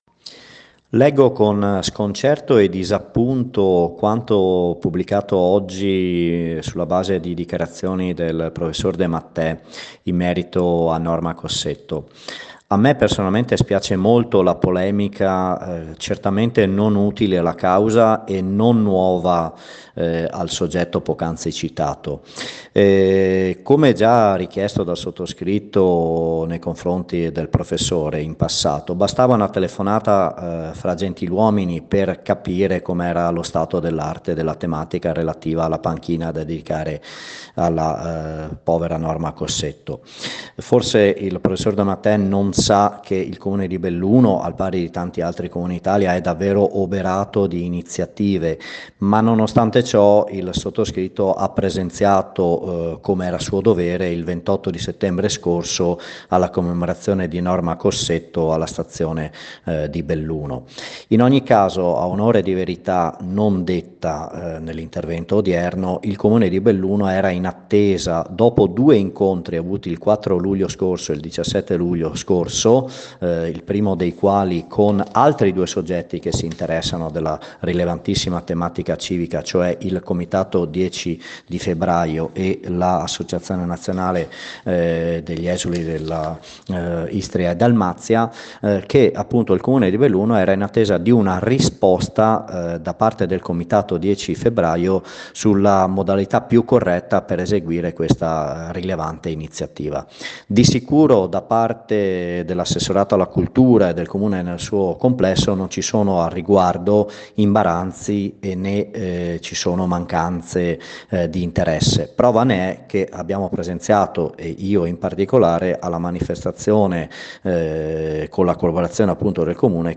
BELLUNO L’assessore Raffaele Addamiano commenta una “polemica” non nuova.